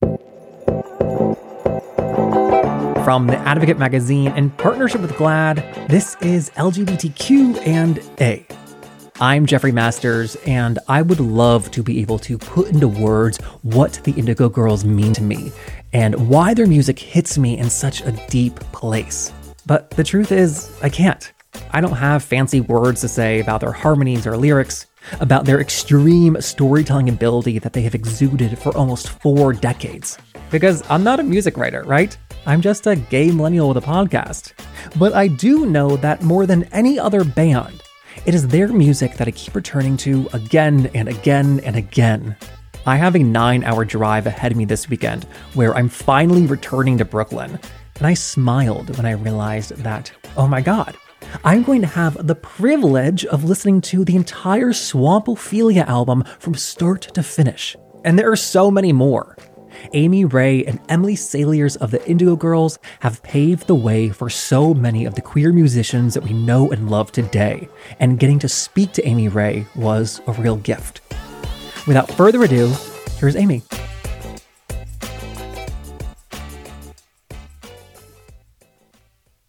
(captured from the webcast)
01. introduction (1:19)